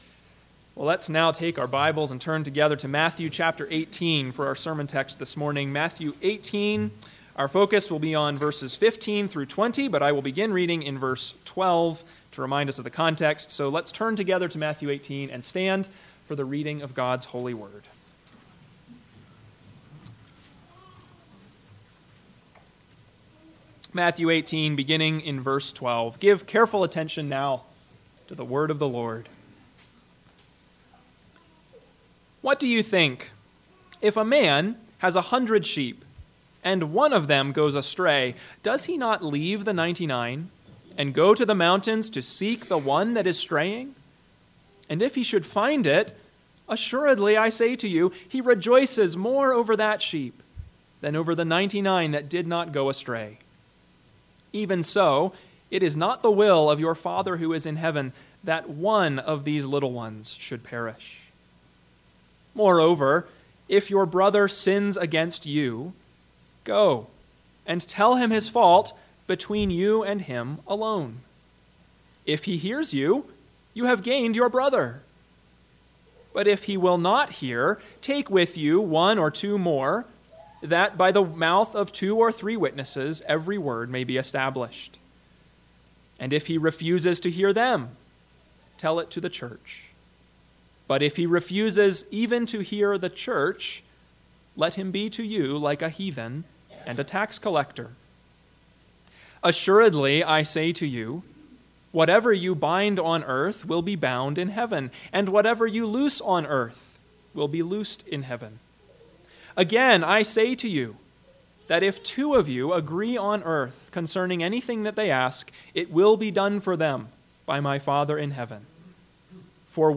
AM Sermon – 5/12/2024 – Matthew 18:15-20 – Northwoods Sermons